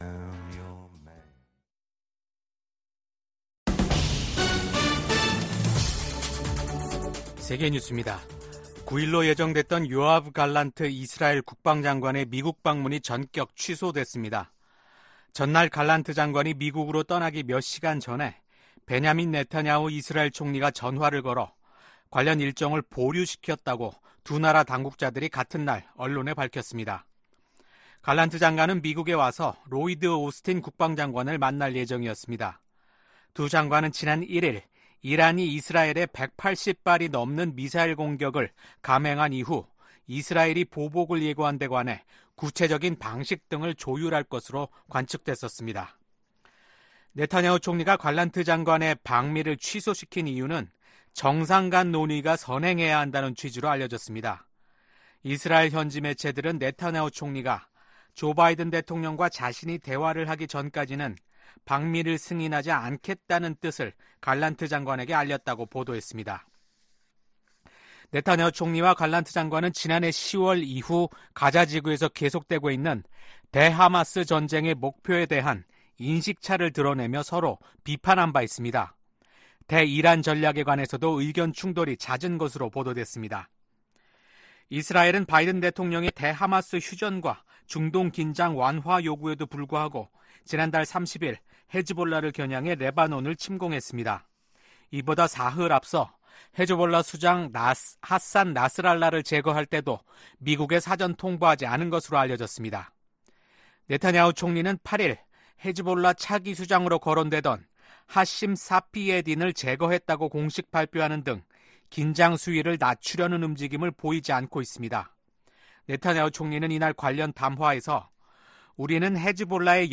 VOA 한국어 아침 뉴스 프로그램 '워싱턴 뉴스 광장' 2024년 10월 10일 방송입니다. 북한은 한국과 연결되는 도로와 철도를 끊고 한국과의 국경을 영구 차단하는 공사를 진행한다고 선언했습니다. 군축과 국제안보를 담당하는 유엔총회 제1위원회에서 북한의 대량살상무기 개발과 북러 군사협력에 대한 규탄이 이어지고 있습니다. 북한이 우크라이나 도네츠크 지역에 인력을 파견했다는 보도와 관련해 미 국방부는 북한의 대러 지원 움직임을 주시하고 있다고 강조했습니다.